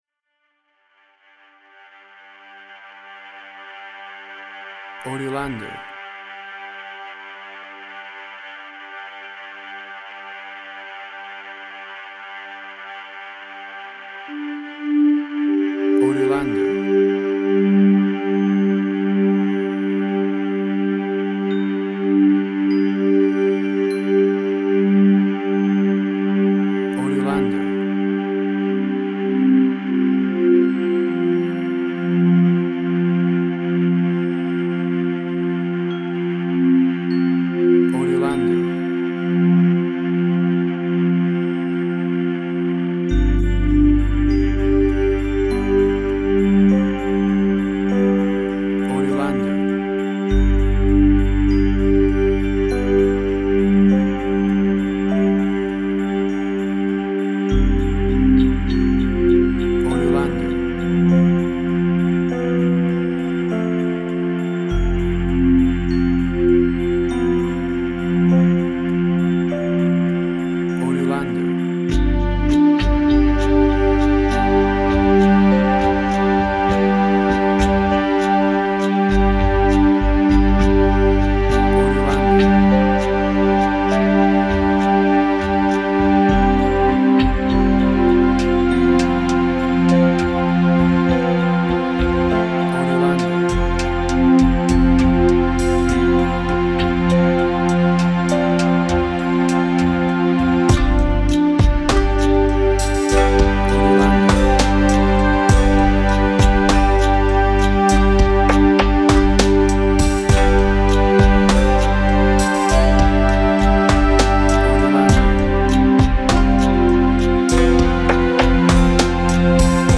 New Age
Tempo (BPM): 50